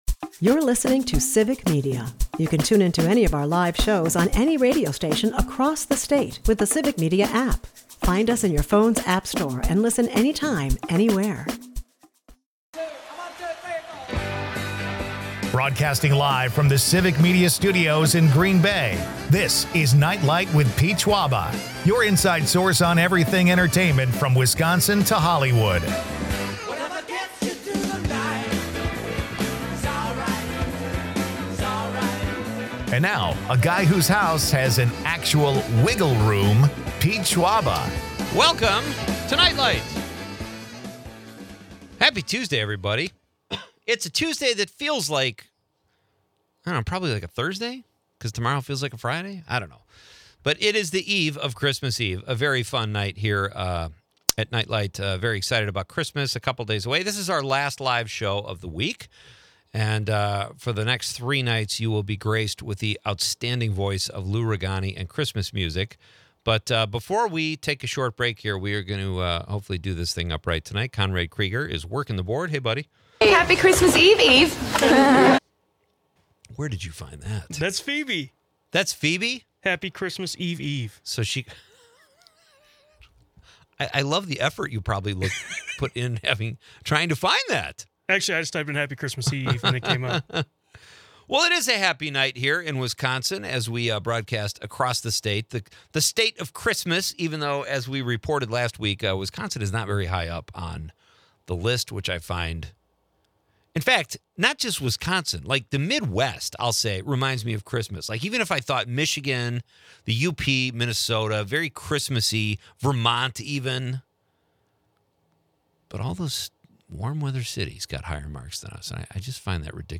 The show features live music by the duo 7000apart, who recount their international love story and musical journey from open mics to releasing a song every month. Highlights include an amusing Bill Murray anecdote, the upcoming Festivus celebration, and a pre-Christmas activity debate with listeners.